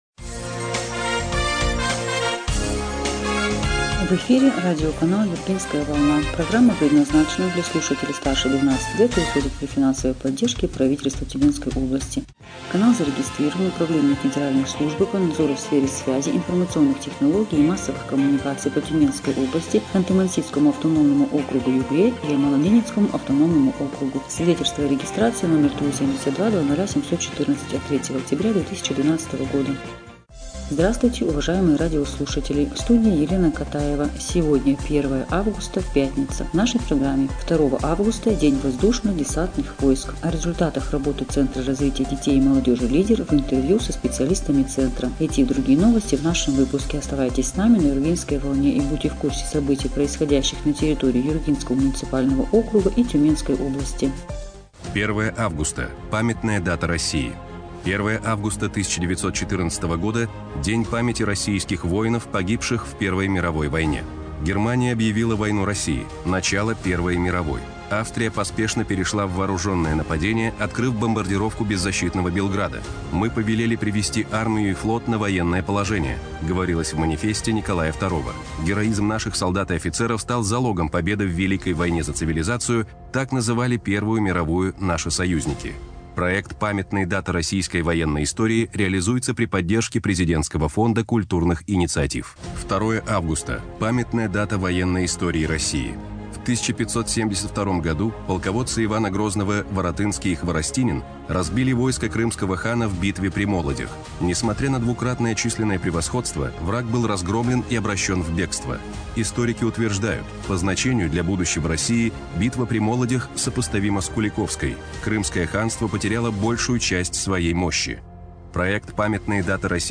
-О работе Центра развития детей и молодежи "Лидер" – в интервью со специалистами.